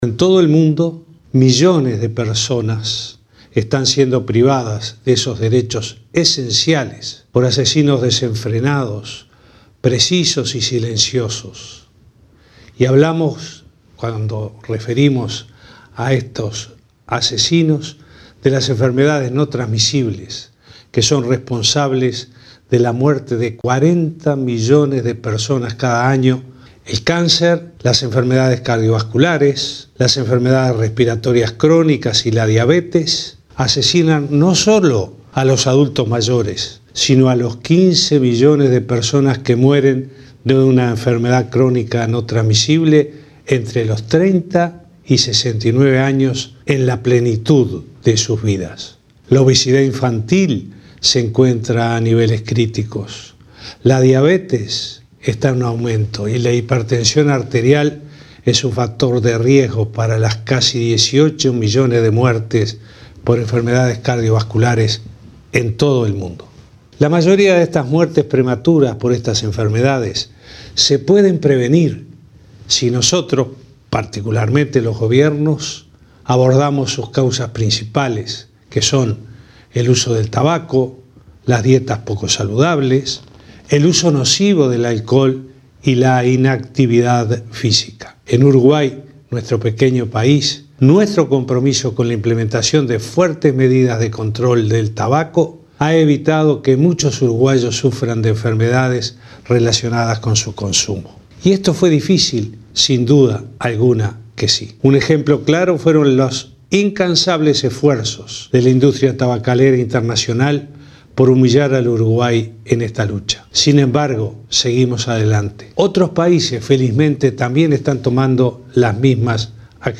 En su mensaje para la OMS sobre tabaquismo, pidió compromiso mundial con el tema y recordó que las medidas antitabaco de Uruguay evitaron muertes.